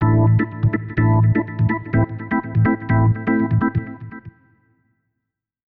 ORGAN012_VOCAL_125_A_SC3(R).wav